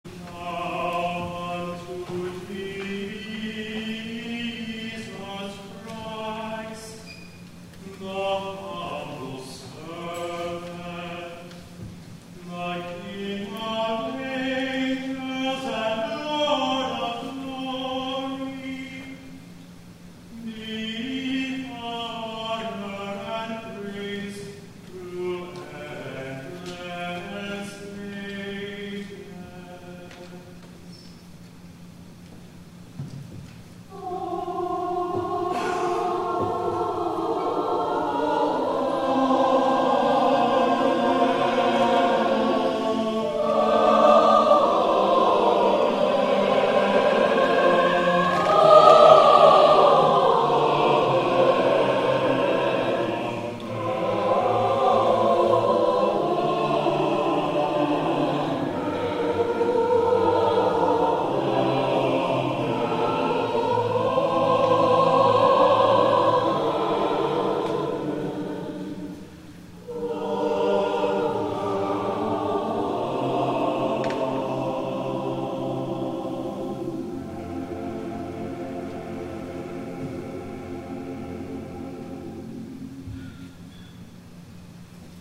TRINITY SUNDAY
*THE CHORAL RESPONSE